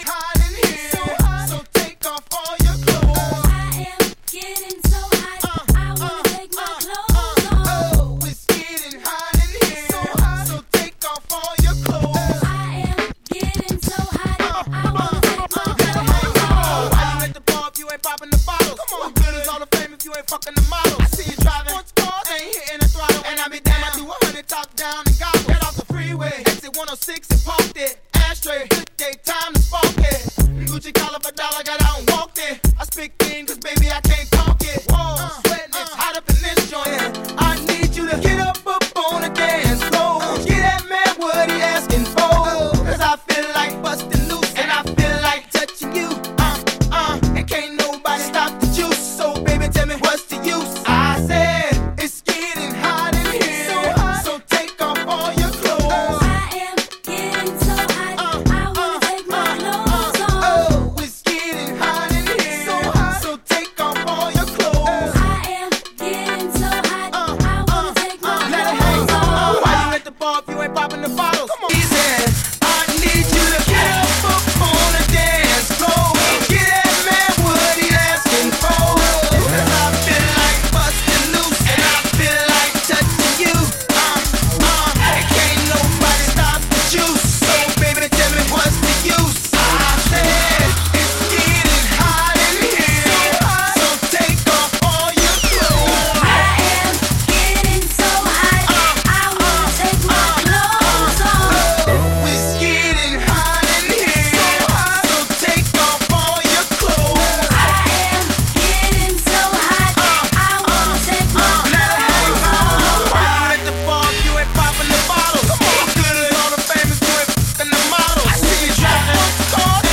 Category: Drum N Bass